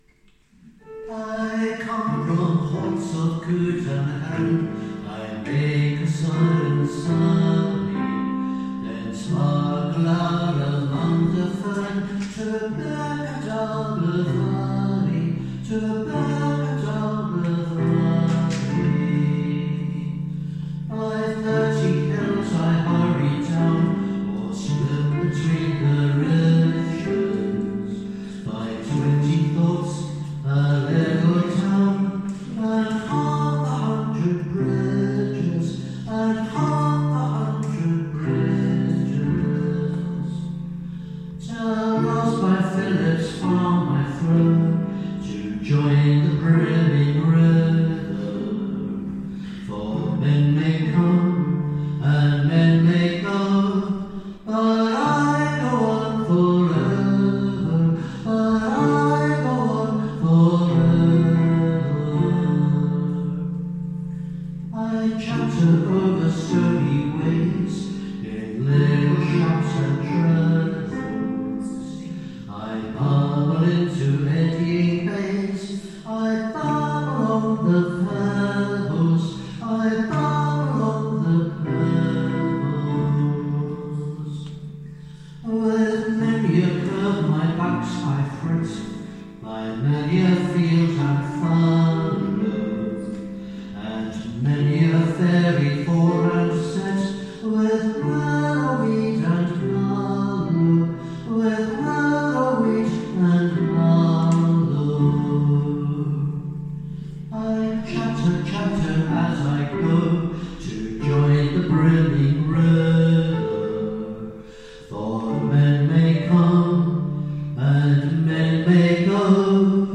Concerts with Band of Brothers and Family